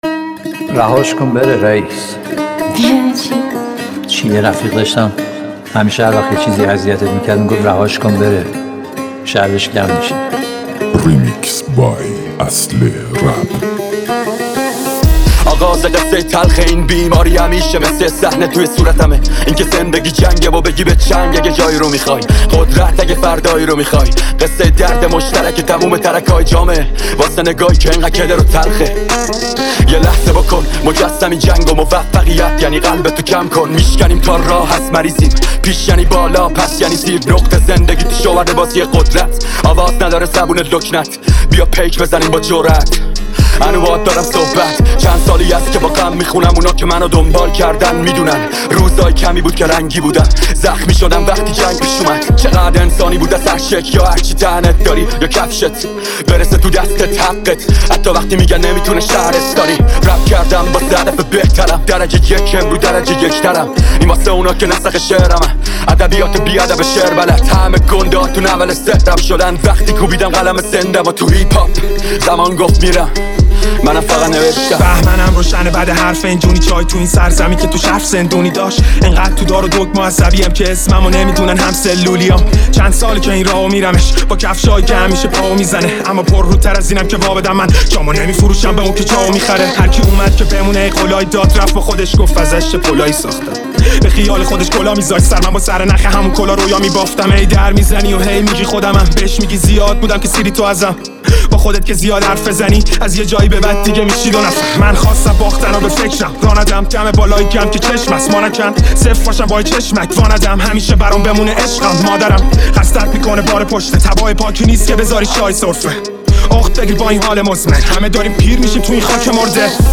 یه ریمیکس غمگین 🖤